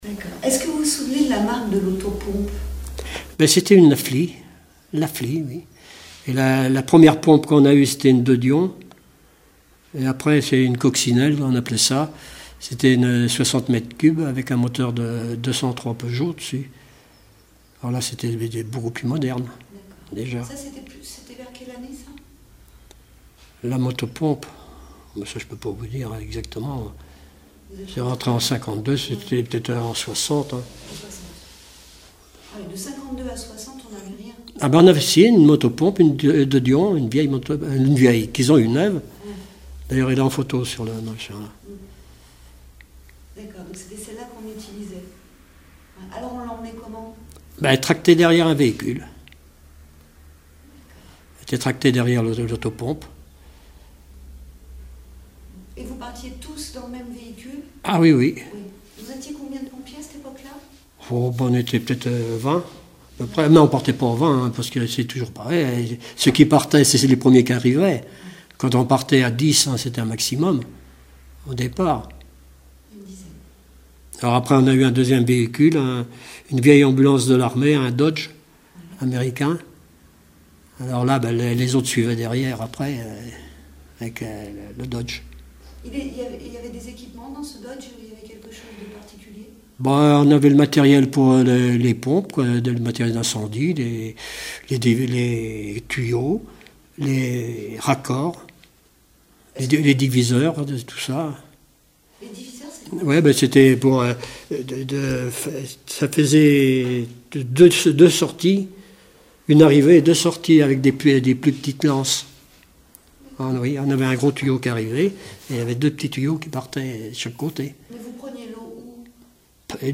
Témoignages d'un ancien sapeur-pompier
Catégorie Témoignage